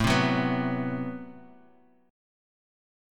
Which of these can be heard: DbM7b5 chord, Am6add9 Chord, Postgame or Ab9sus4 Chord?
Am6add9 Chord